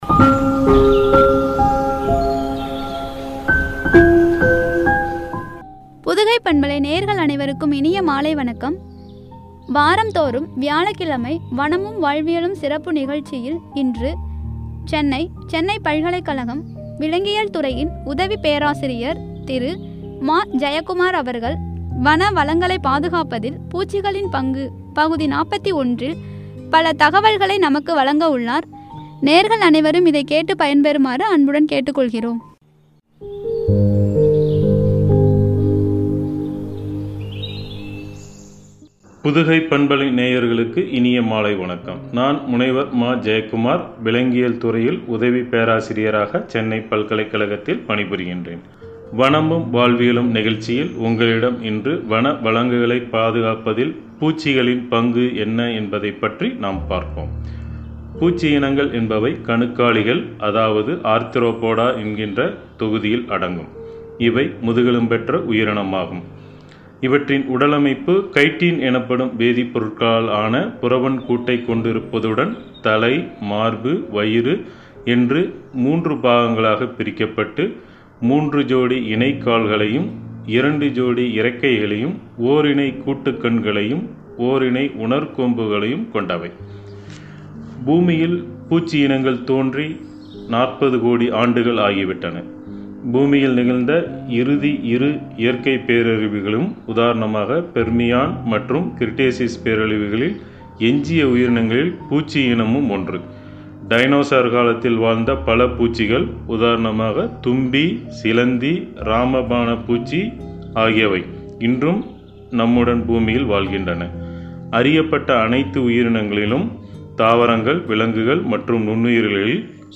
குறித்து வழங்கிய உரை.